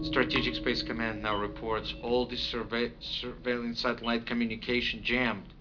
At 1:11:28 on the DVD, actor Yakov Smirnoff seems to stumble a bit over the word "surveilling" in his dialog.